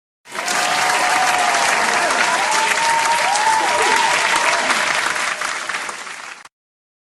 Âm thanh "Tiếng vỗ tay" | Hiệu ứng âm thanh độc lạ ghép và chỉnh sửa video